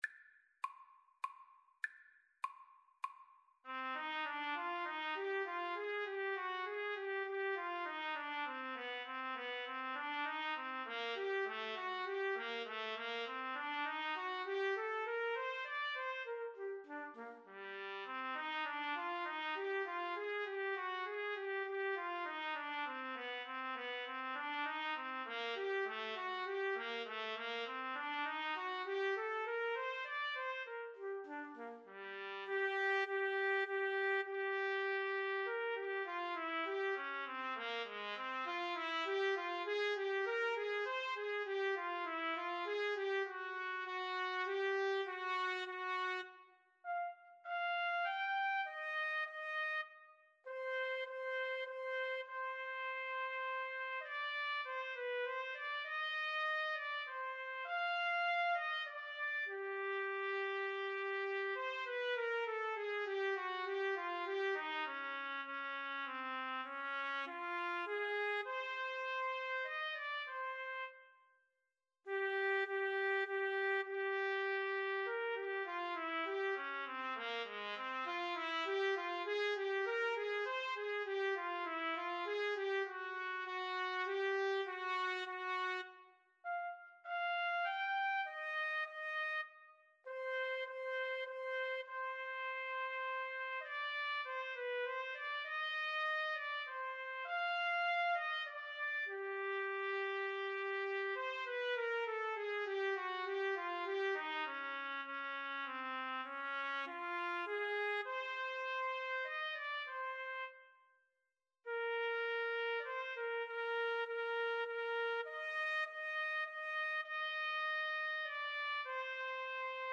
3/4 (View more 3/4 Music)
Moderato
Classical (View more Classical Trumpet Duet Music)